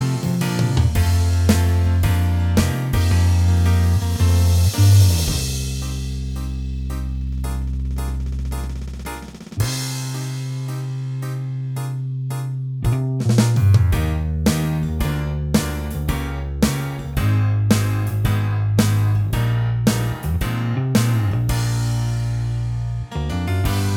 Minus Guitars Rock 3:07 Buy £1.50